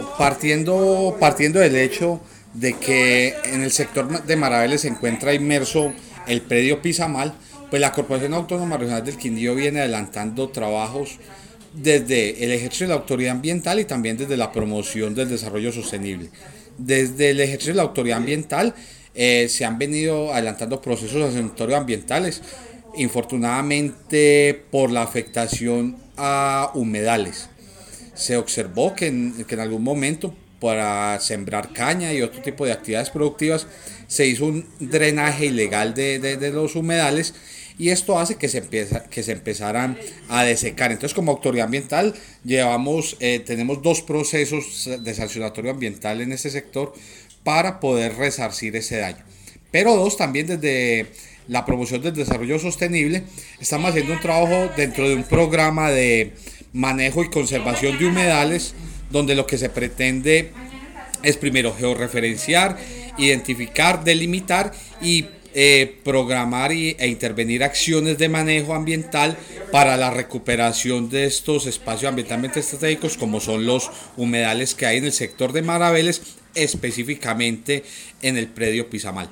AUDIO DIRECTOR GENERAL DE LA CRQ-JOSÉ MANUEL CORTÉS OROZCO: